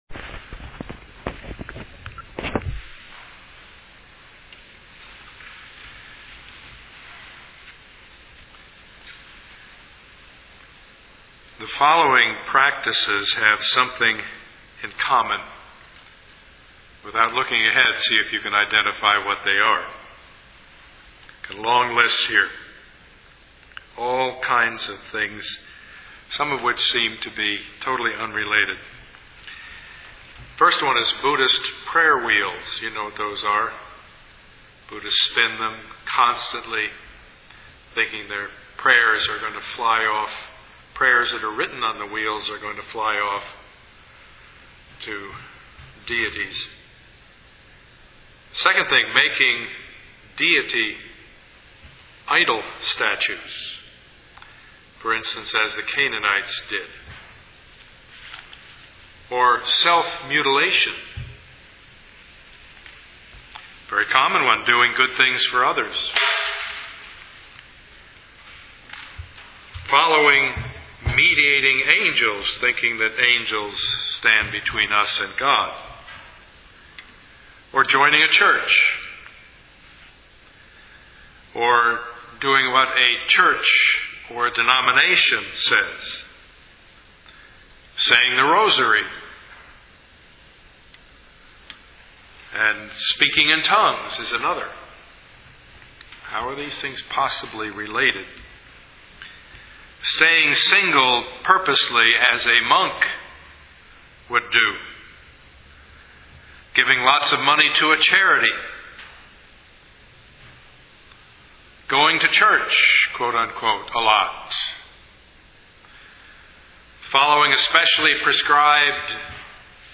Service Type: Sunday morning
Part 1 of the Sermon Series